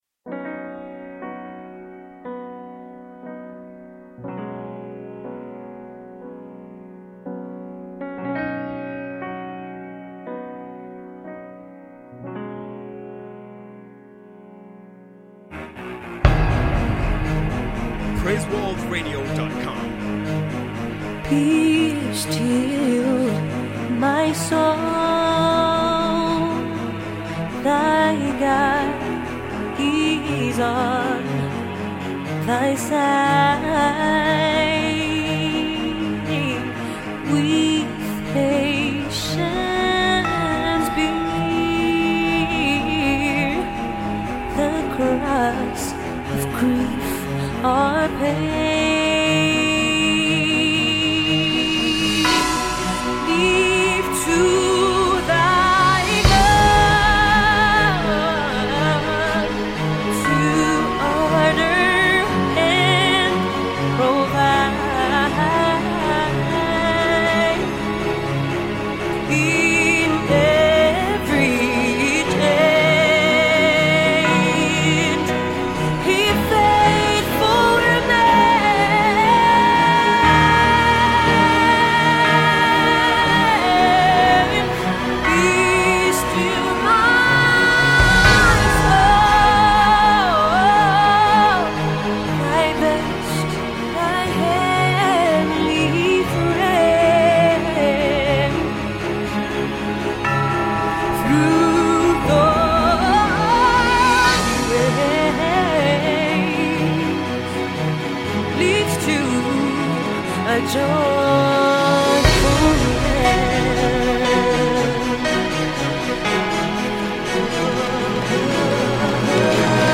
an intense, dramatic, soul lifting hymn